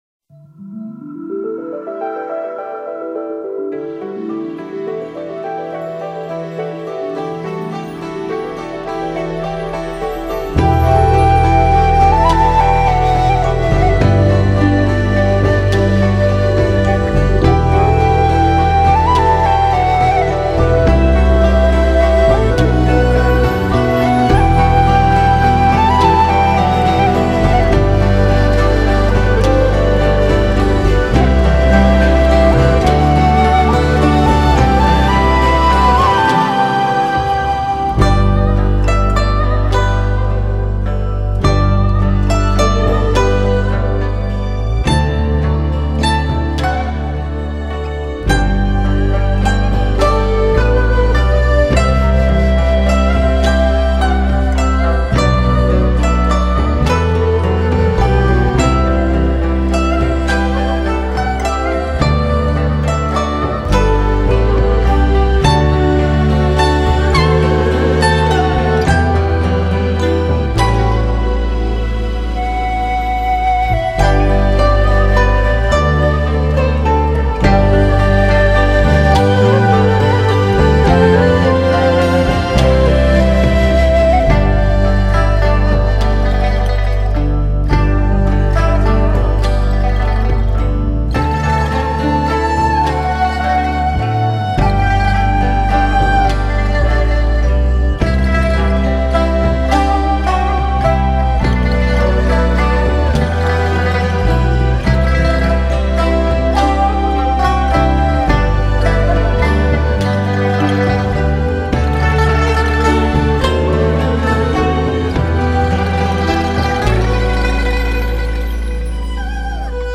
音乐类型： 民乐　　　　　　　　　　　　.
全球首张使用中国民族乐器演绎的圣诞专辑，包含12首超级经典好听的圣诞曲目，.